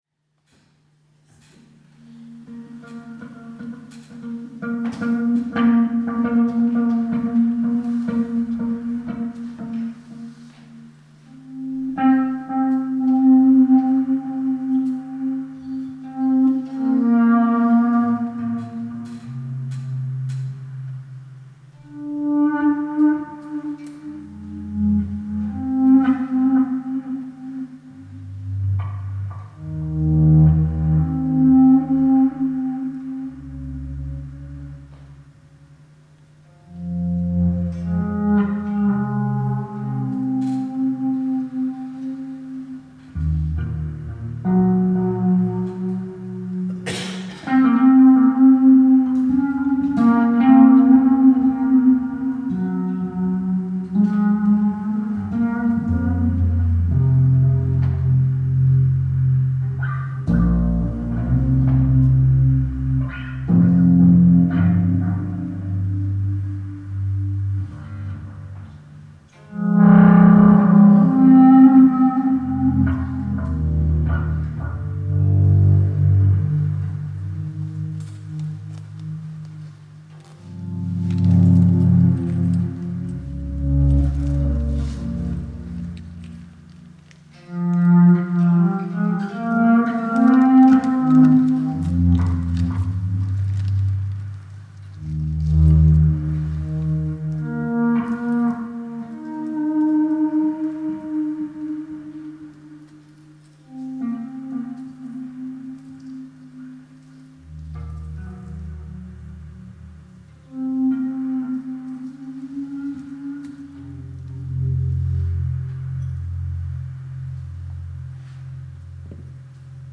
live fil från sept 2013, Kulturgjuteriet, Borrby